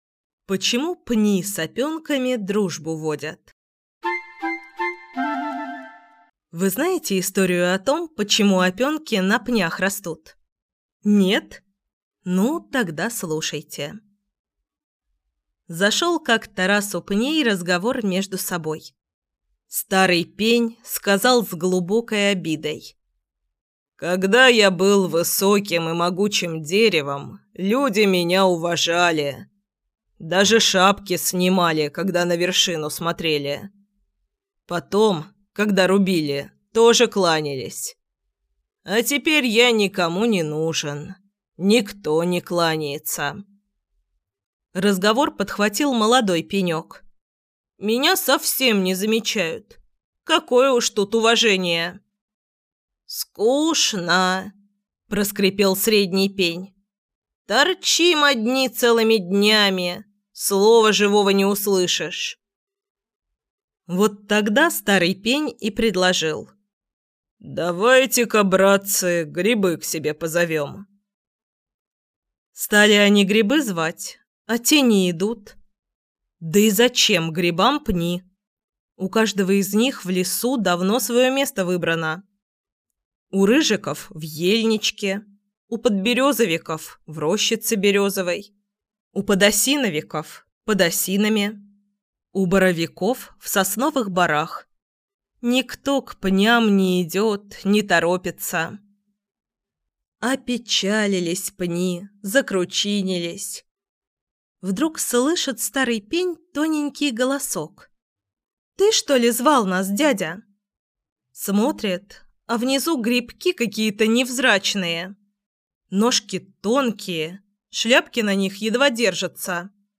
Аудиокнига Сказки русского леса | Библиотека аудиокниг